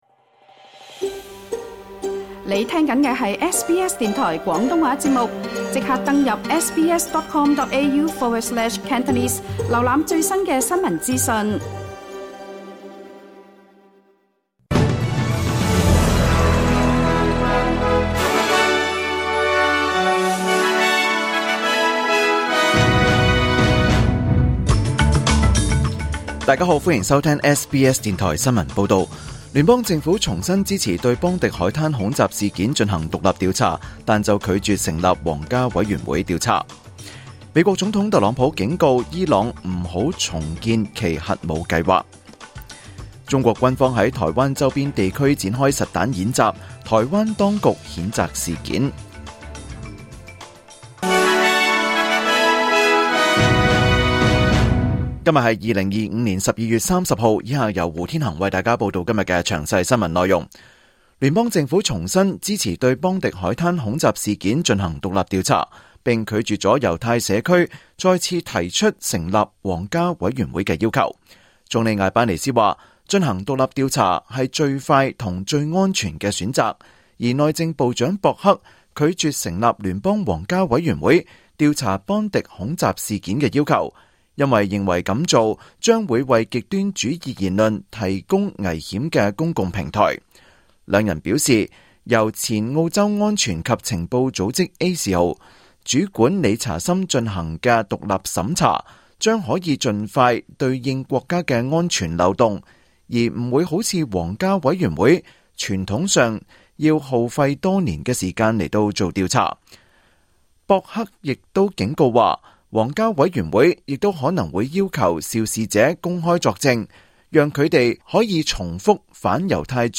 2025 年 12 月 30 日 SBS 廣東話節目詳盡早晨新聞報道。